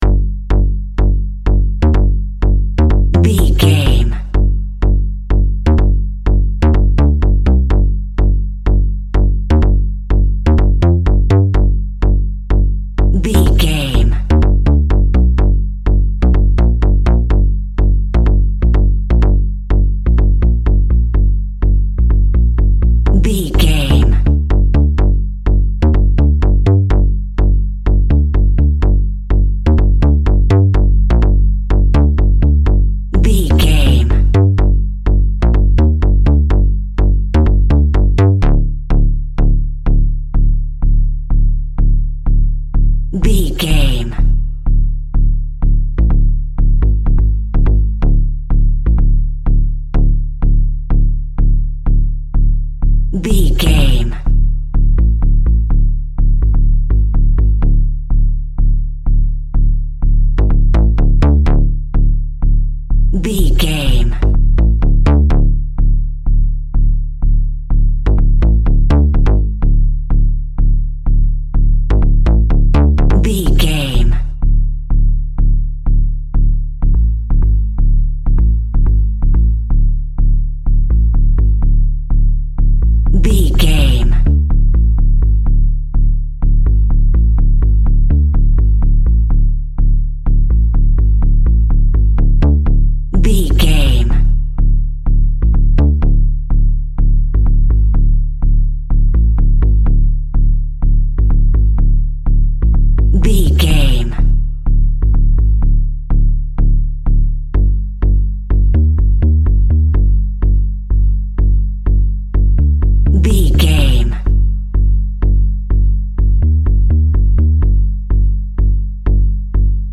In-crescendo
Aeolian/Minor
ominous
dark
eerie
Horror synth
synthesizer